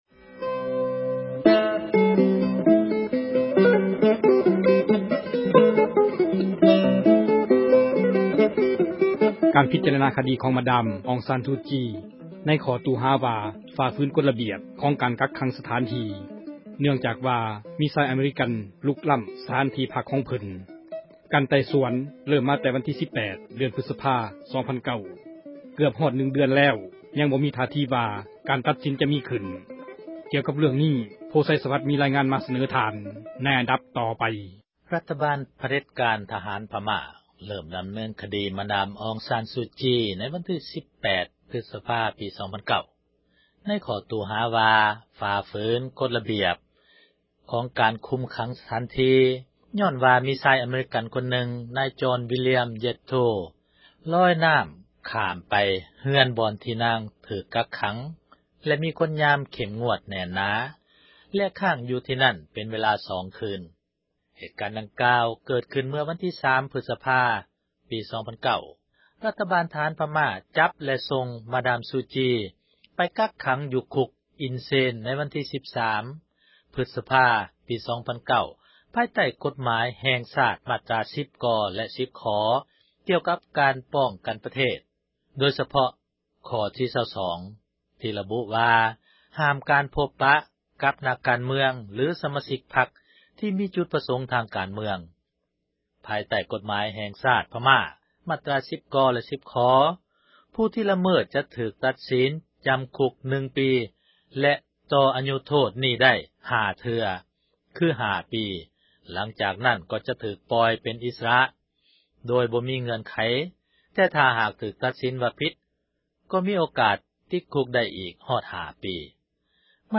ມີລາຍງານ ໃນອັນດັບ ຕໍ່ໄປ.